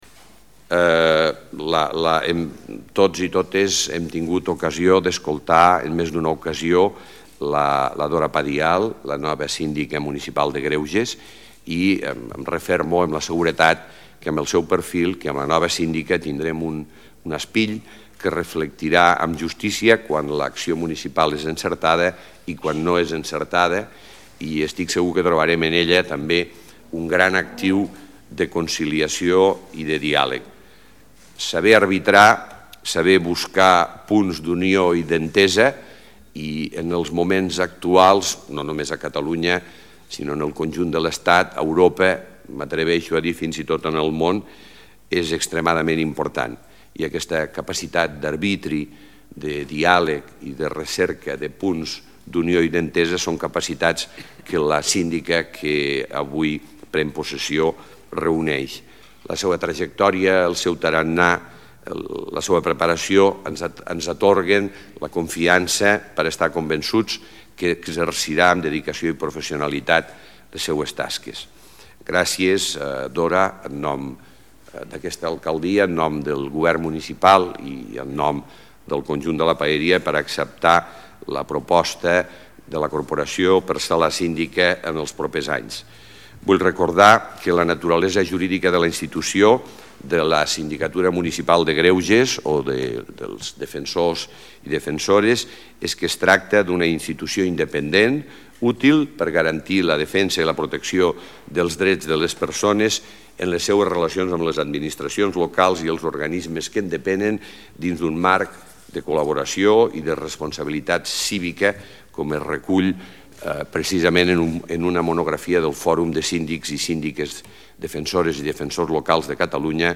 paraules-de-lalcalde-miquel-pueyo-en-la-presa-de-possesio-de-la-nova-sindica-de-la-paeria-dora-padial-2